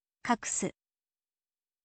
kakusu